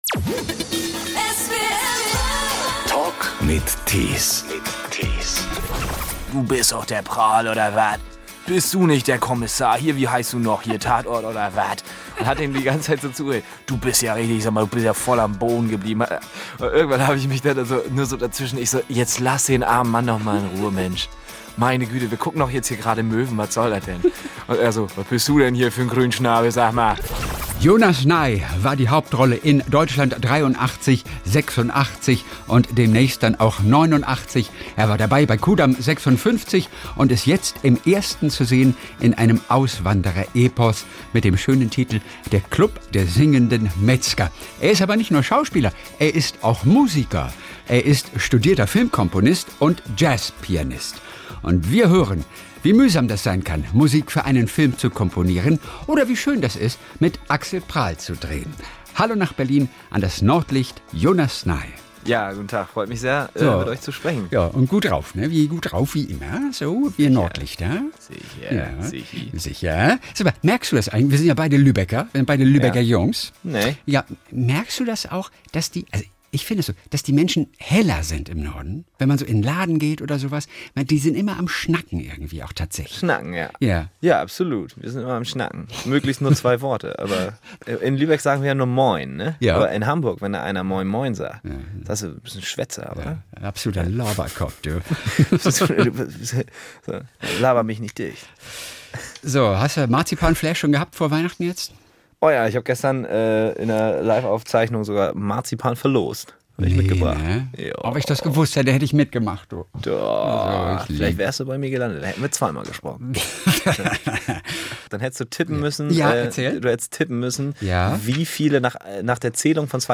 Der Talk in SWR 3